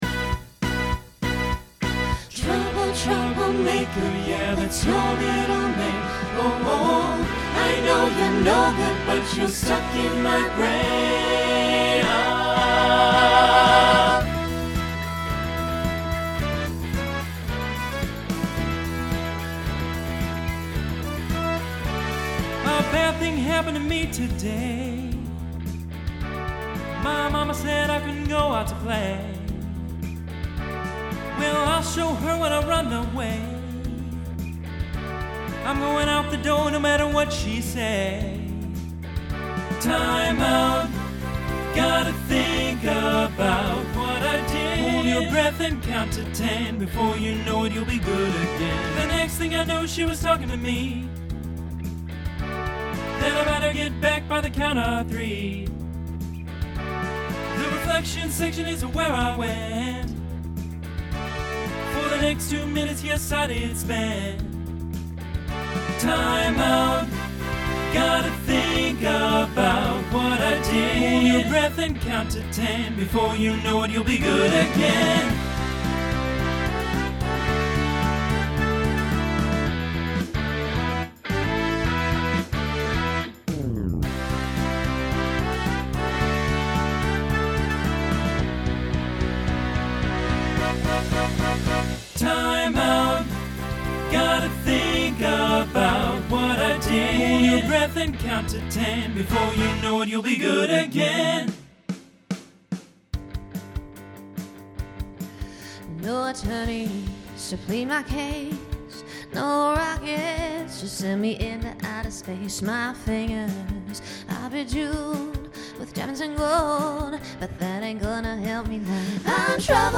Genre Pop/Dance
Transition Voicing Mixed